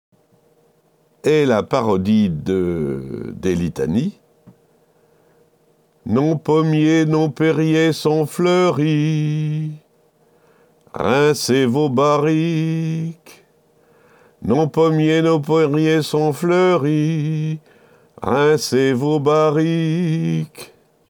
Mais à l’arrière, d’autres paroles se chantonnaient sur les mêmes airs.